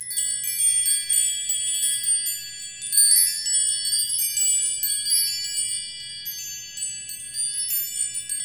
Index of /90_sSampleCDs/Roland L-CD701/PRC_Asian 2/PRC_Windchimes
PRC CHIME06L.wav